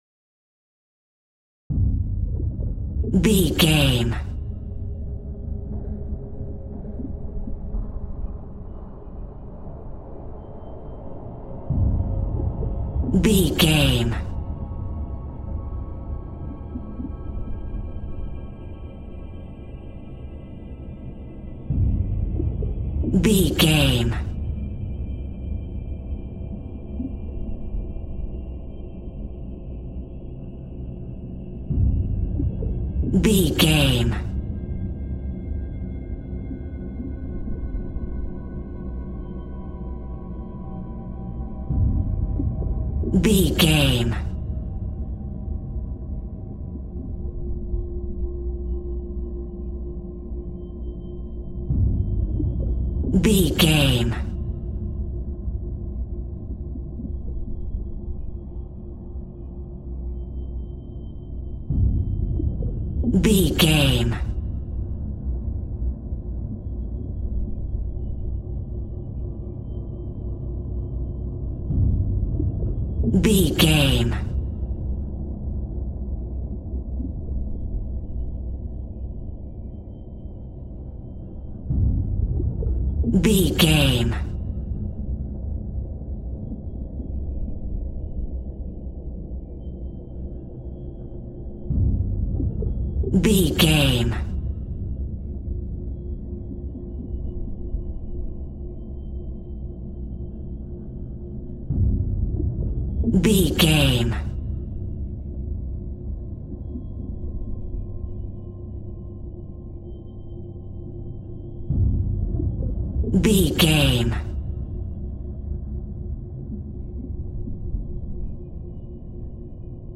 Atonal
Slow
scary
tension
ominous
dark
suspense
eerie
synthesiser
horror
Synth Pads
Synth Strings
synth bass